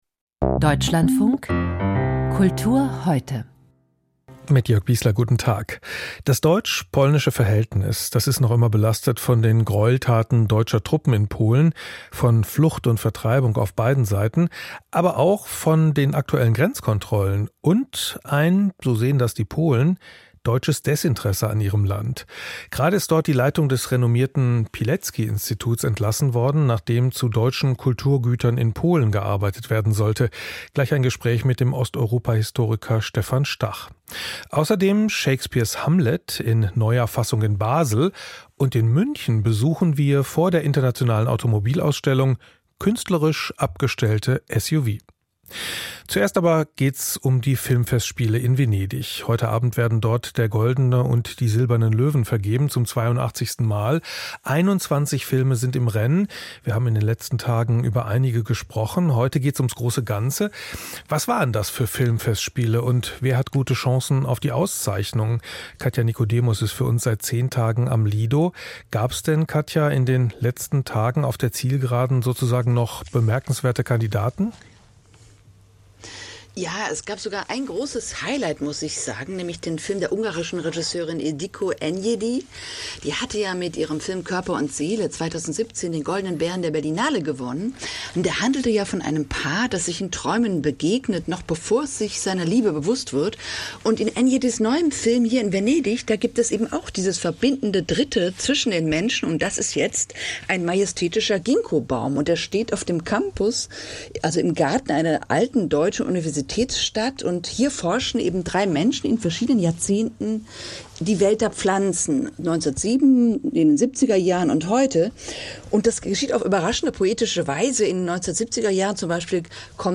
Das Kulturmagazin liefert einen aktuellen Überblick über Kulturereignisse des Tages. Es bietet eine schnelle und direkte Reaktion auf Theater- und Filmpremieren, Ausstellungseröffnungen, Diskussionsforen und Kulturveranstaltungen im In- und Ausland. Darüber hinaus greift die Sendung auch kulturpolitische Probleme, Tendenzen und Phänomene in Form von Hintergrundberichten, Kommentaren und Glossen auf.